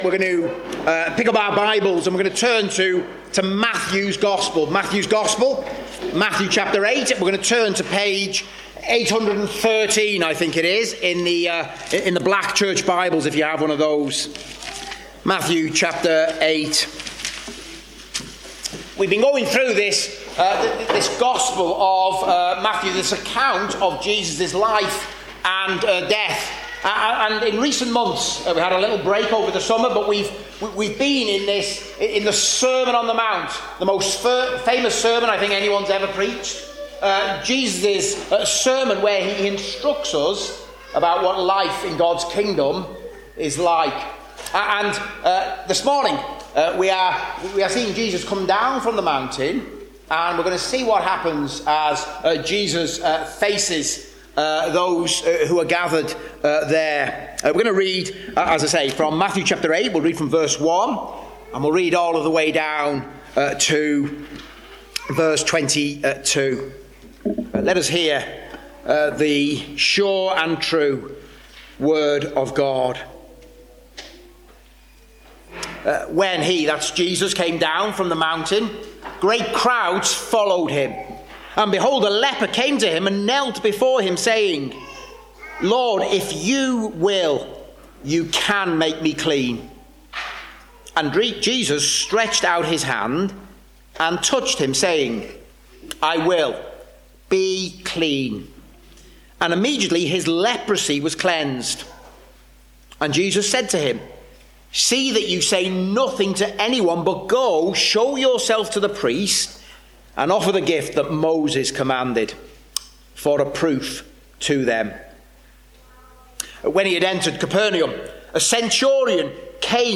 Service Type: Preaching